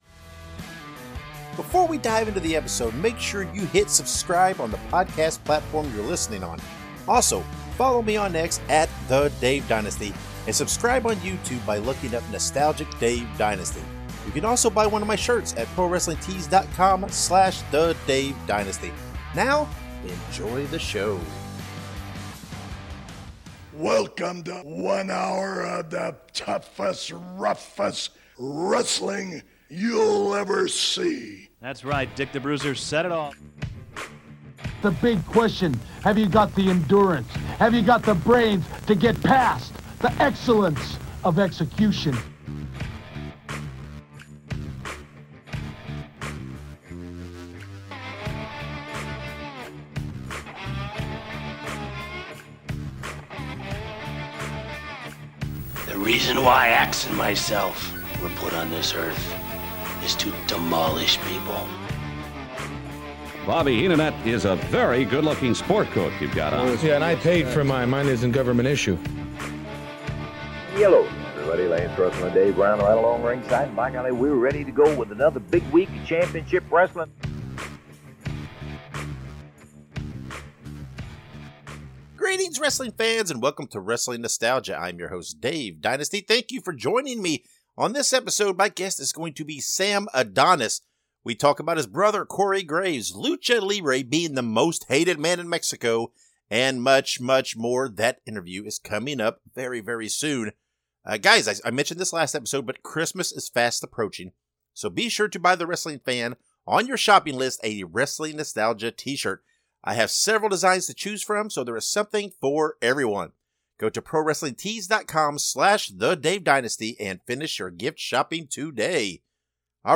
(Puppeteers/Puppet Builders)